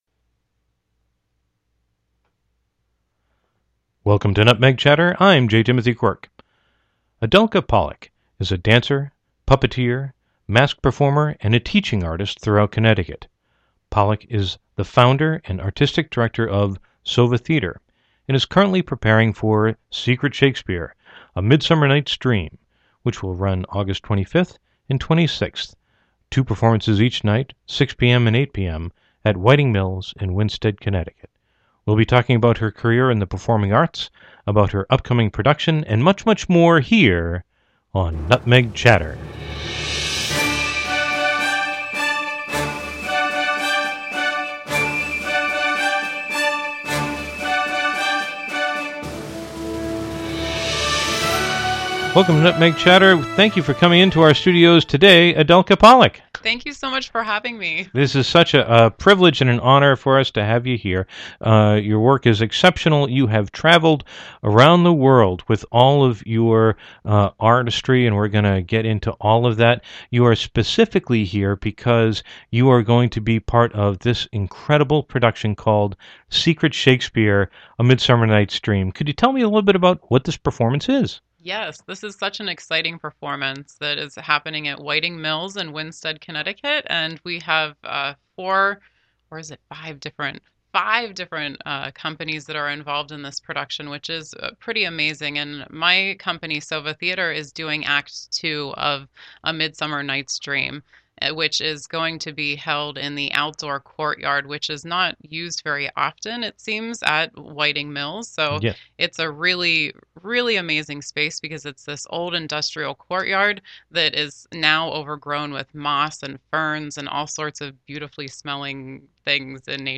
Categories: Dance, Radio Show, Theater